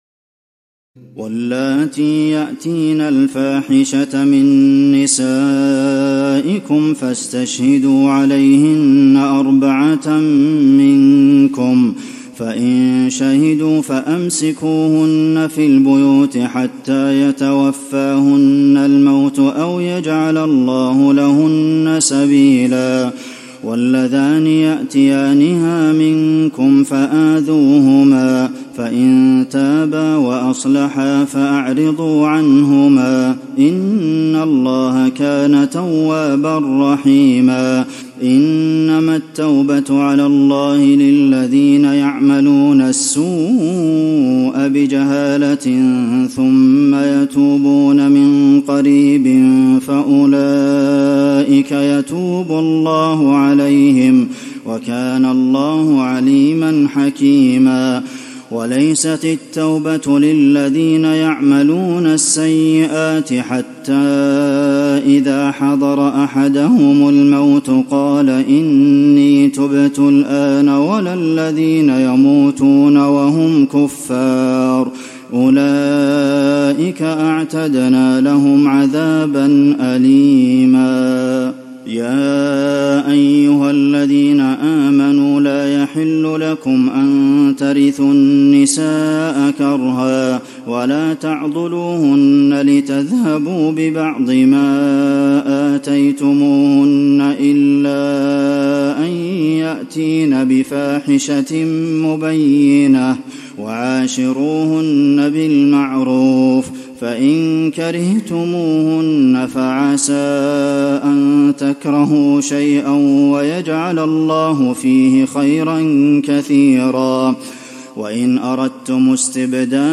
تراويح الليلة الرابعة رمضان 1435هـ من سورة النساء (15-70) Taraweeh 4 st night Ramadan 1435H from Surah An-Nisaa > تراويح الحرم النبوي عام 1435 🕌 > التراويح - تلاوات الحرمين